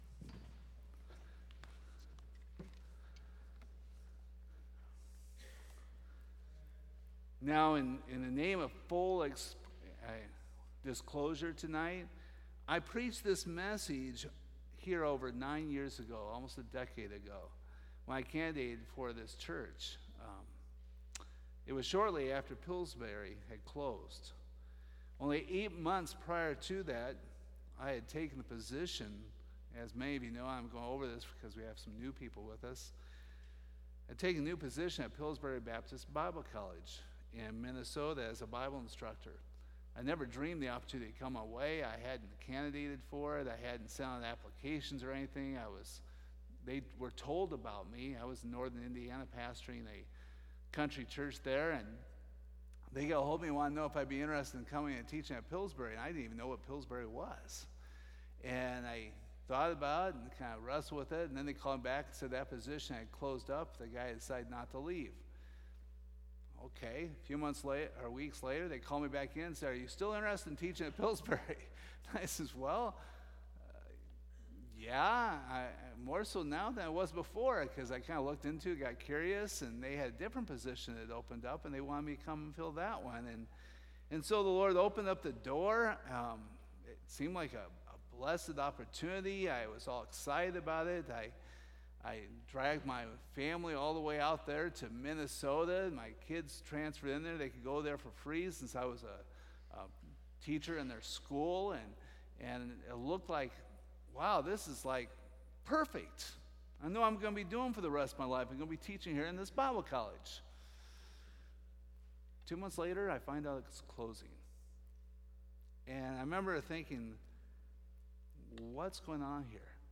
Service Type: Sunday Evening Topics: God's Omniscience and Man's lack of foresight , God's Will , Living for God , Transgression « Moving on to Maturity Top Fire Insurance Plans.